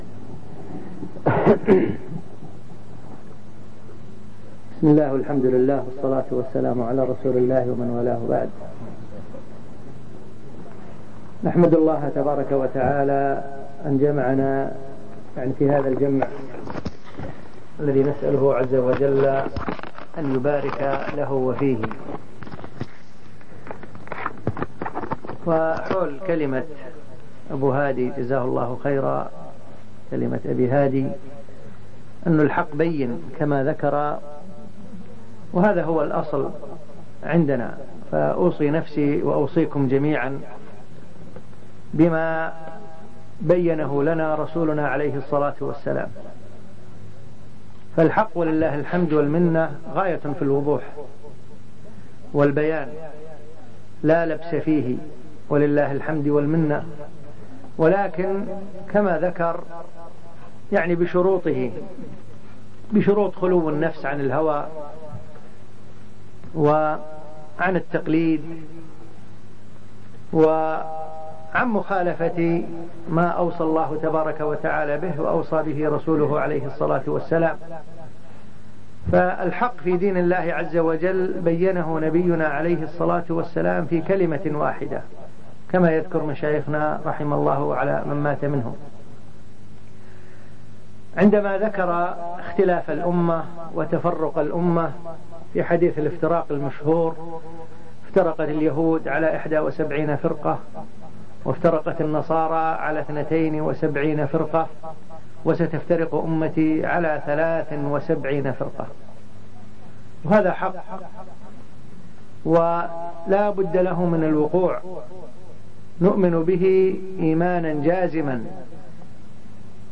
الخميس 9 ذي القعدة 1424 جلسة في بر النعيرية